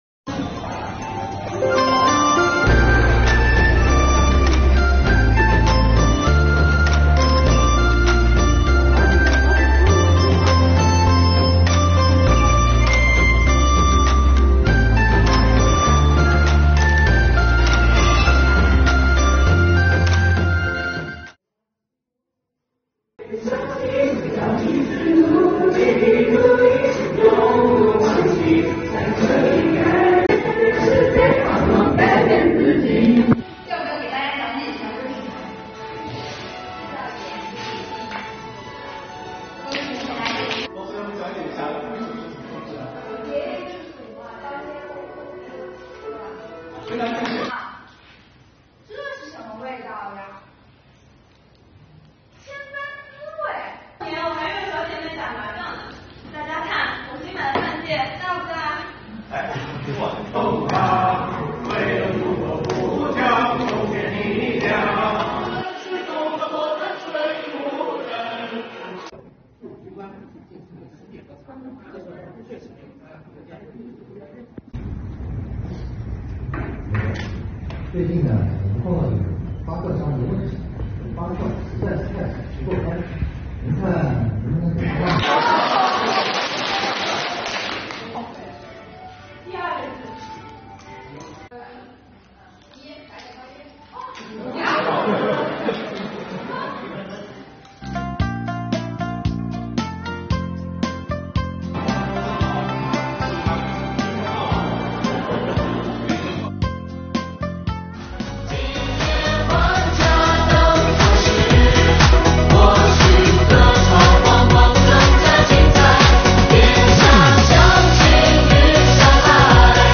2月3日，在元宵节到来之际，崇明区税务局“礼赞新时代 欢喜闹元宵”活动在长兴岛税务食堂举行，共同感受中国民俗文化魅力，激发青年干部队伍活力和团队凝聚力，积极营造喜庆、祥和的节日氛围。